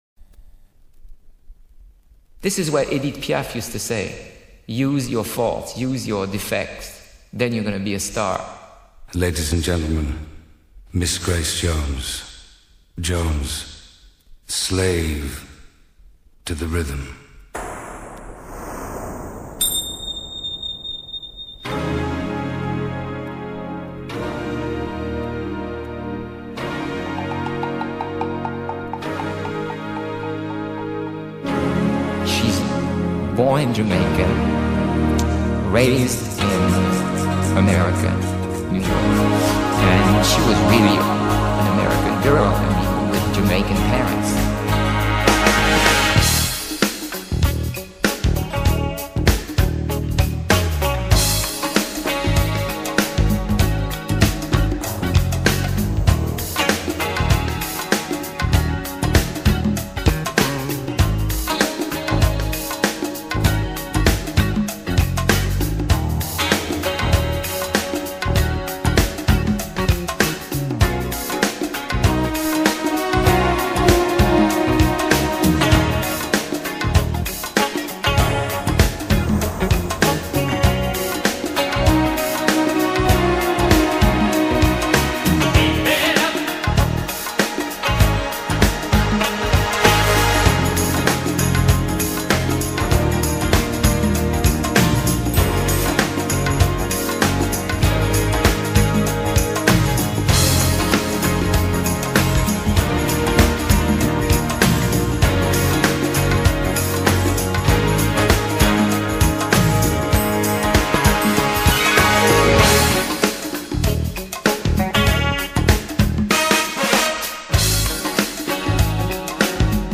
New Wave, Post-Disco, Art Rock